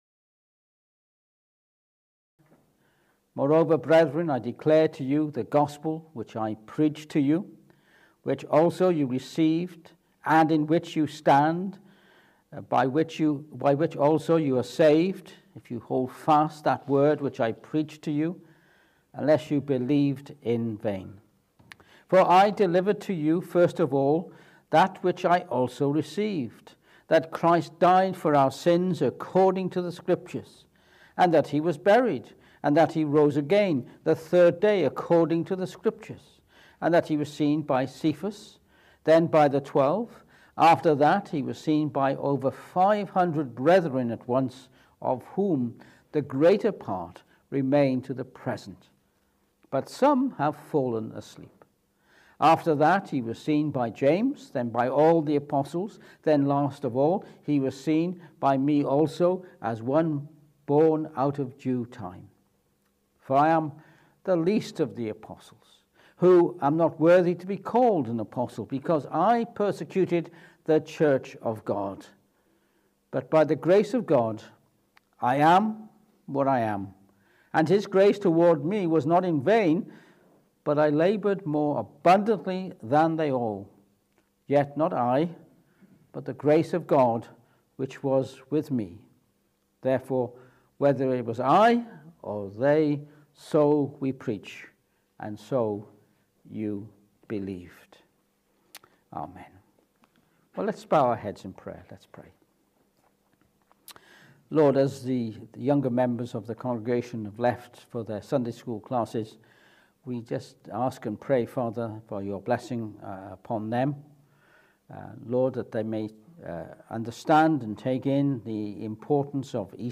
1 Corithians 15:1-11 Service Type: Morning Service This morning we will be reading from 1 Corinthians 15:1-11 as we remember the resurrection.